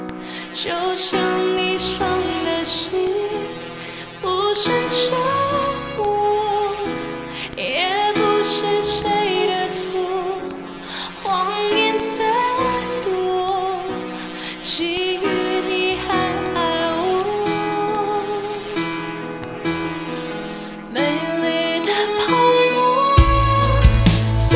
femaleWM.wav